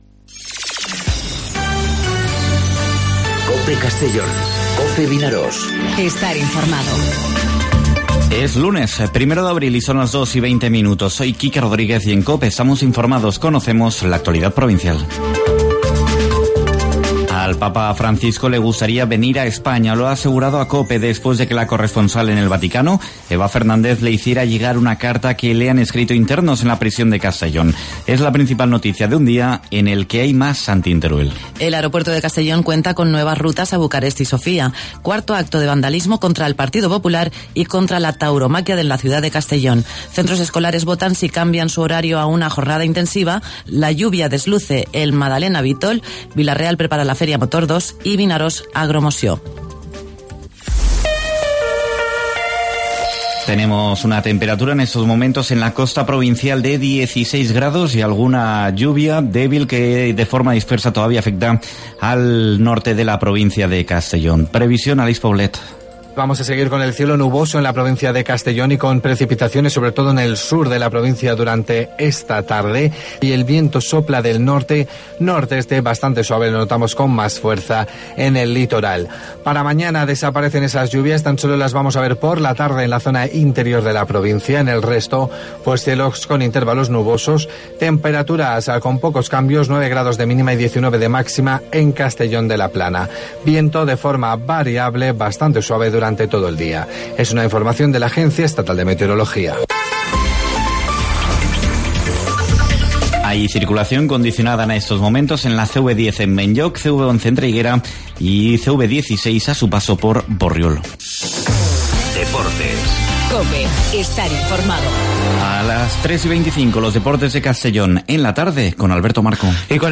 Informativo 'Mediodía COPE' en Castellón (01/04/2019)